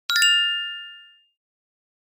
9. Minimal Alert Notification Tone
This minimal alert sound is simple but very effective for message notifications.
minimal_alert_notification_tone.mp3